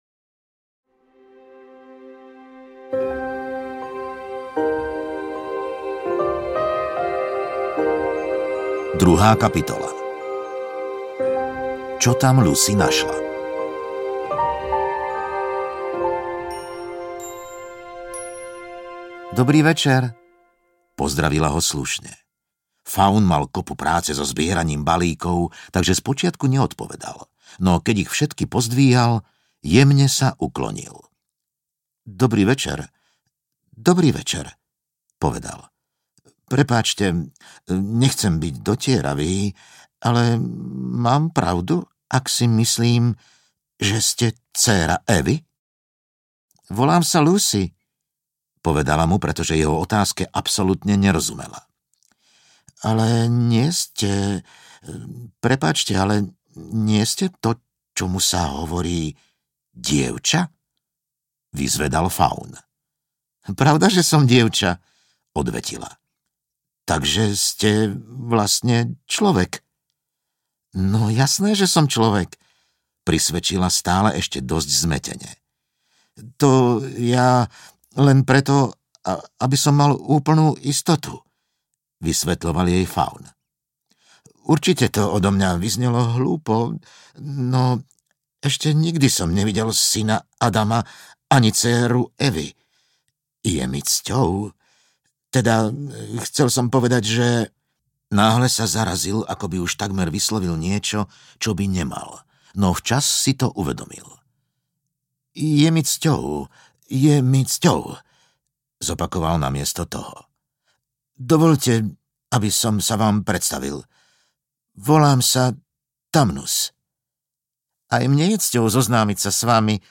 Kroniky Narnie – Lev, šatník a čarodejnica audiokniha
Ukázka z knihy
kroniky-narnie-lev-satnik-a-carodejnica-audiokniha